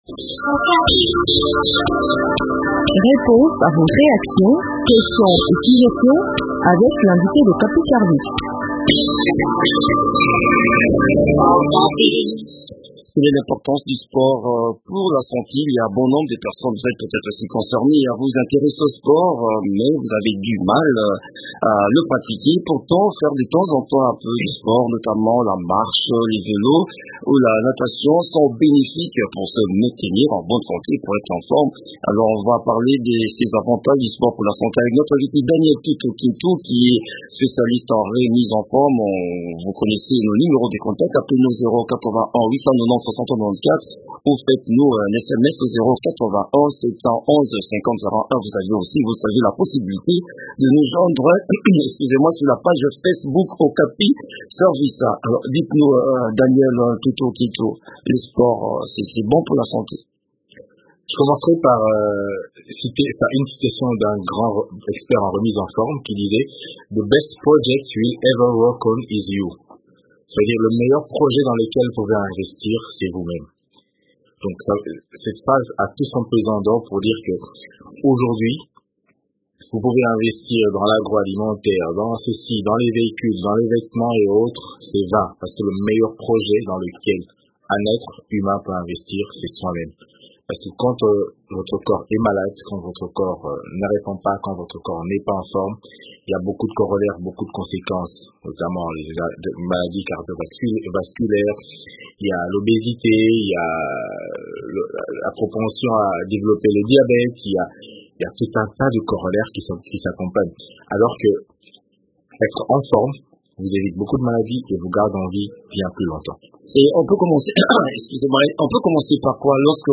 spécialiste en remise en forme est l’invité d’okapi service